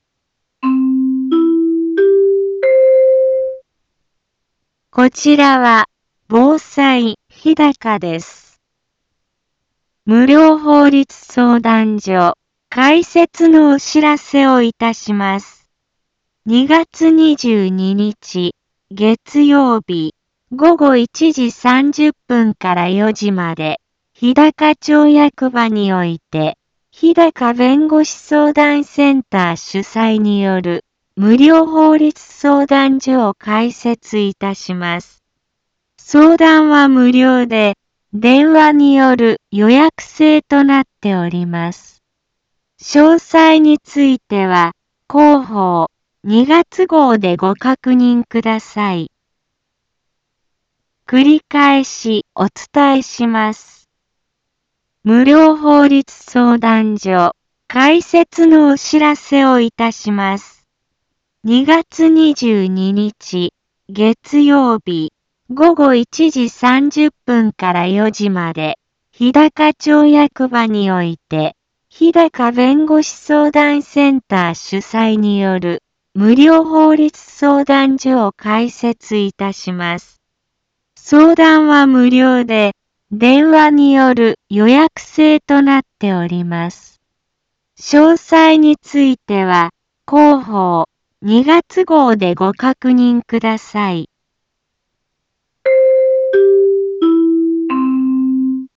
一般放送情報
Back Home 一般放送情報 音声放送 再生 一般放送情報 登録日時：2021-02-15 10:03:30 タイトル：無料法律相談（２月） インフォメーション：無料法律相談所、開設のお知らせをいたします。 ２月２２日、月曜日、午後1時30分から4時まで、日高町役場において、ひだか弁護士相談センター主催による、無料法律相談所を開設いたします。